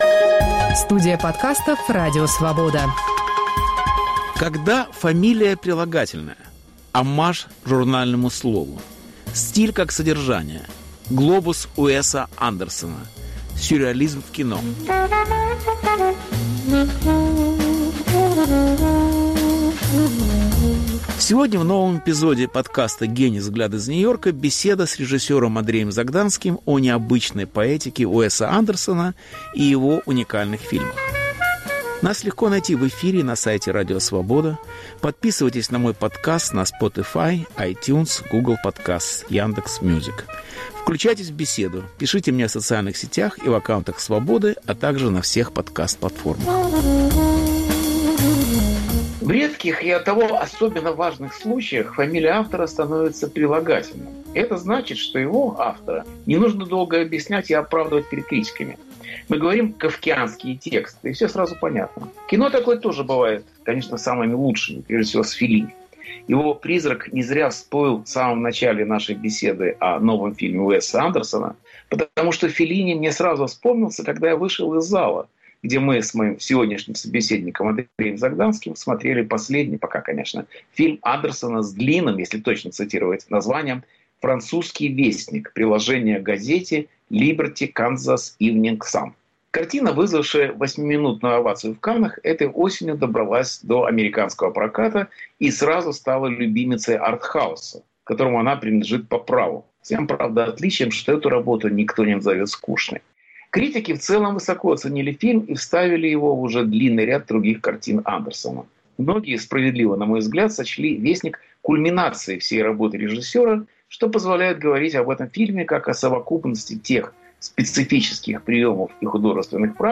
Повтор эфира от 22 ноября 2021 года.